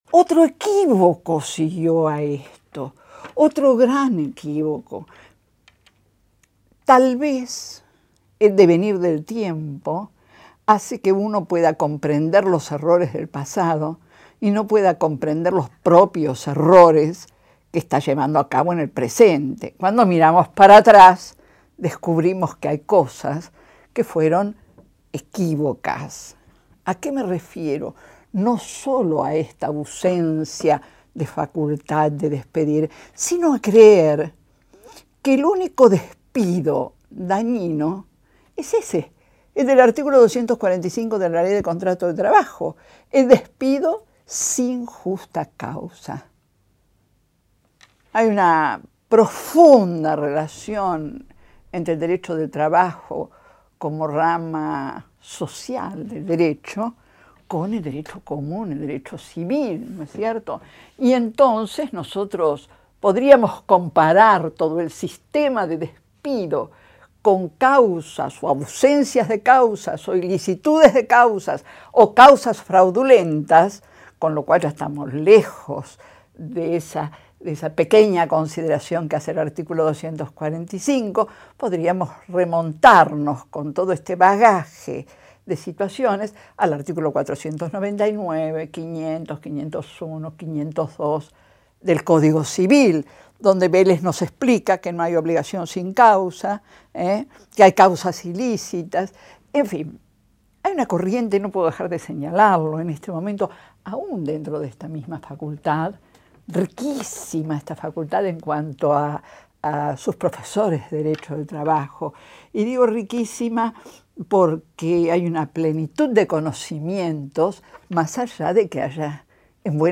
Audio de la clase